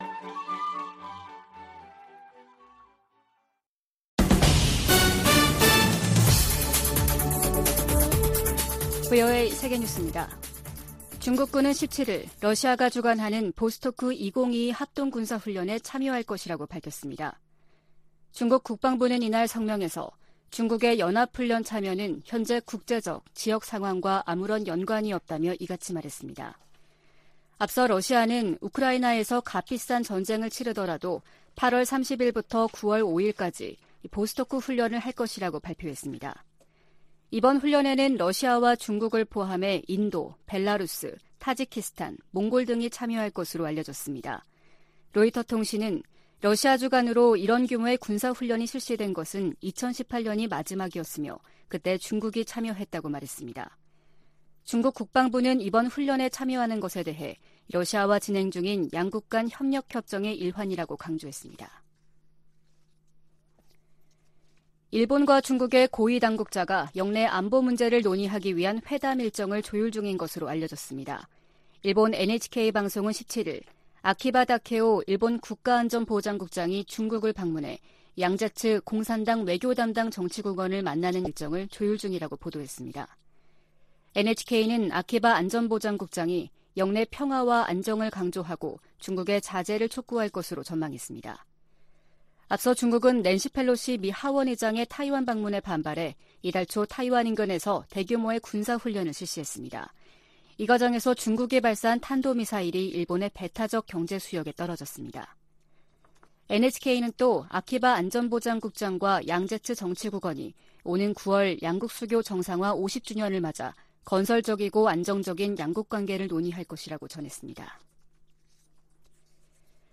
VOA 한국어 아침 뉴스 프로그램 '워싱턴 뉴스 광장' 2022년 8월 18일 방송입니다. 북한이 두 달여 만에 미사일 발사 도발을 재개했습니다. 윤석열 한국 대통령은 광복절 경축사에서 밝힌 담대한 구상 대북 제안은 북한이 비핵화 의지만 보여주면 적극 돕겠다는 것이라며 북한의 호응을 촉구했습니다. 미 국무부는 미국과 한국의 연합 군사훈련이 한국 안보를 지키기 위한 순수한 방어 목적임을 강조했습니다.